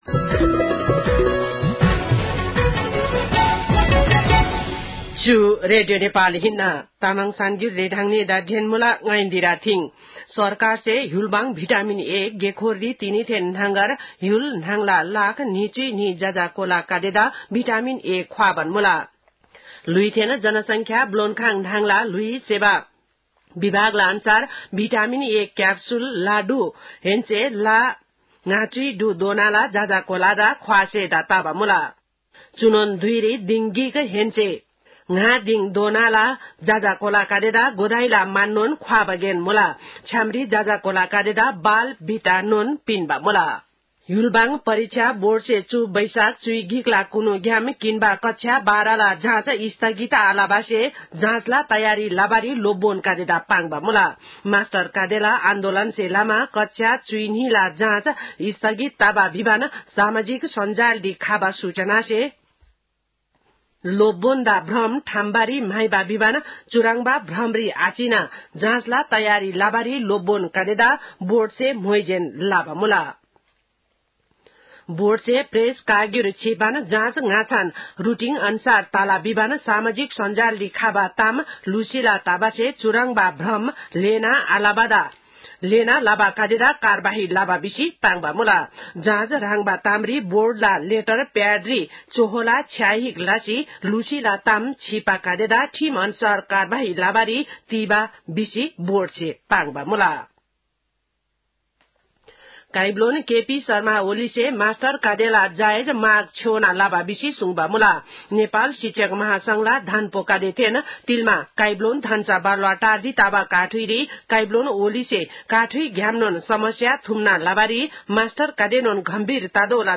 तामाङ भाषाको समाचार : ६ वैशाख , २०८२